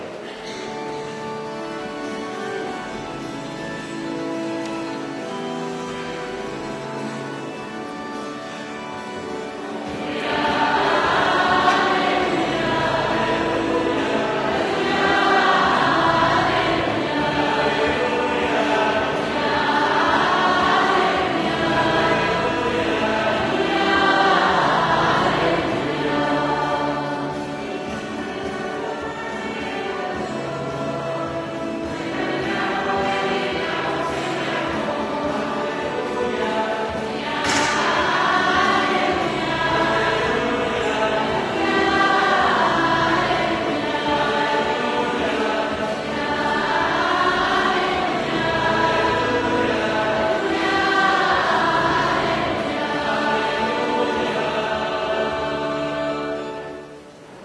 Raduno Giovani 2011 S.Messa – audio